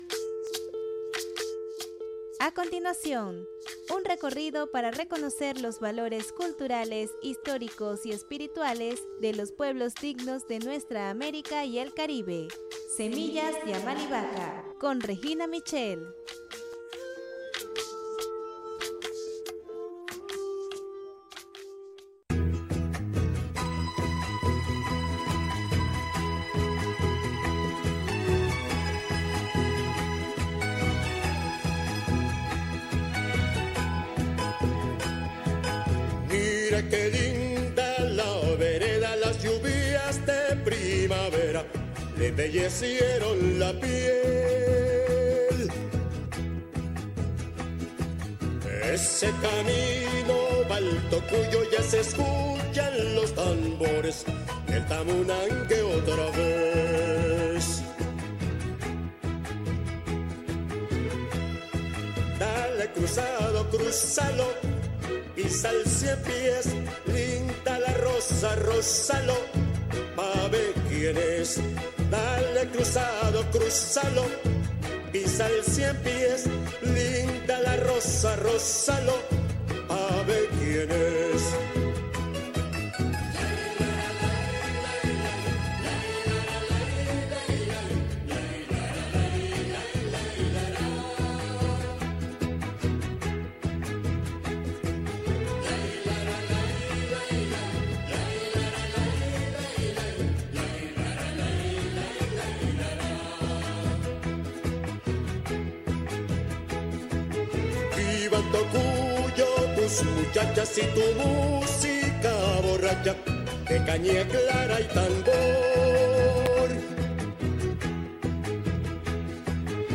Programa grabado